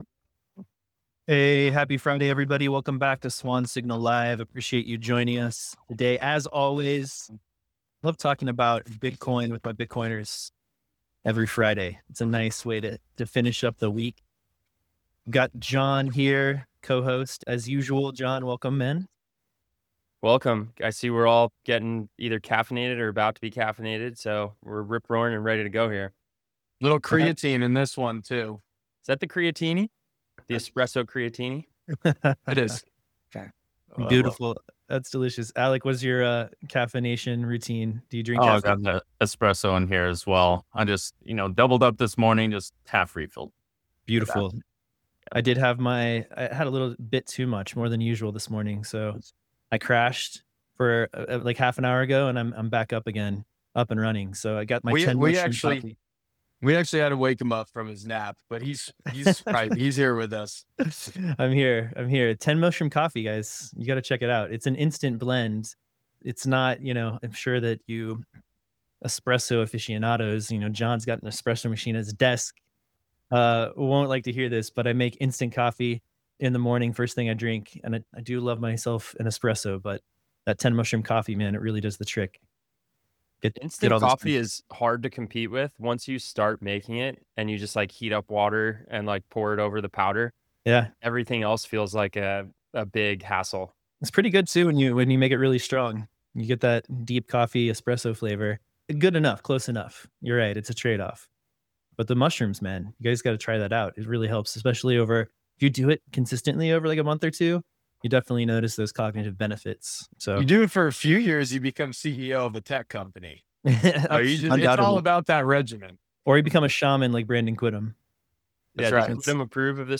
Swan Signal Live brings you interviews, news, analysis, and commentary on Bitcoin, macro, finance, and how Bitcoin is the foundation for a brighter future for us all.